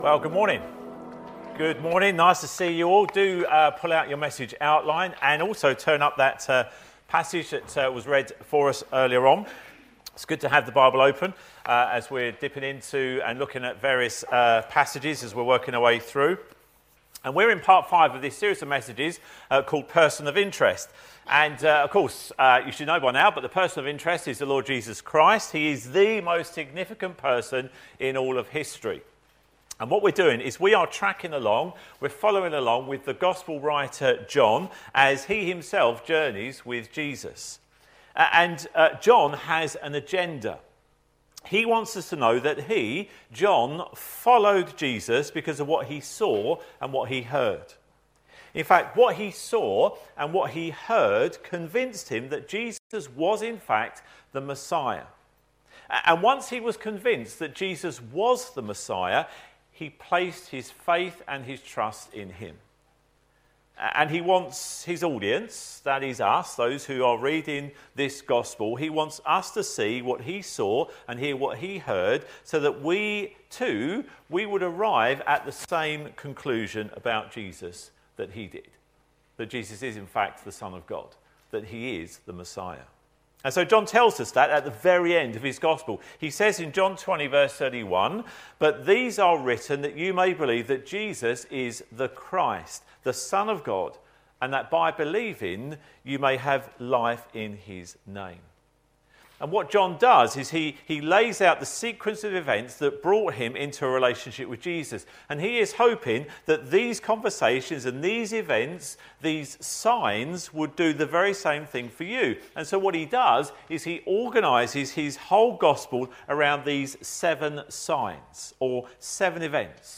How do you deal with fear? Jesus teaches about fear, discover more in this sermon.